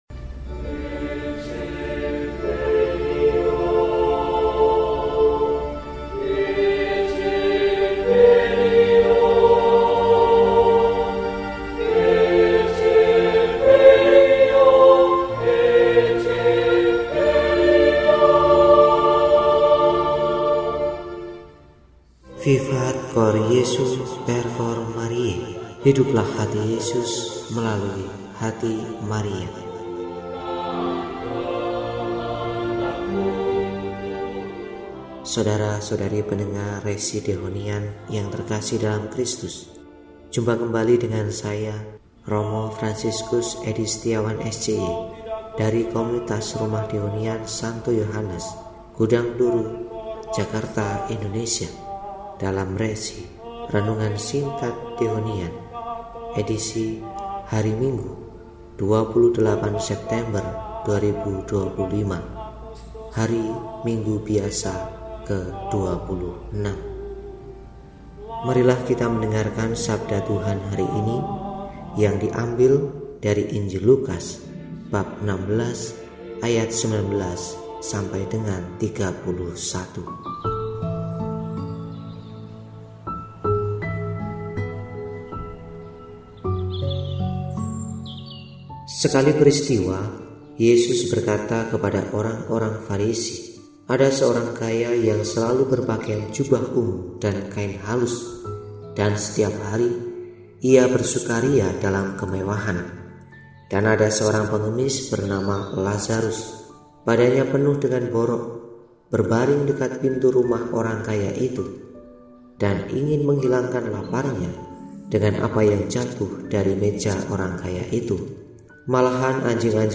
Minggu, 28 September 2025 – Hari Minggu Biasa XXVI – RESI (Renungan Singkat) DEHONIAN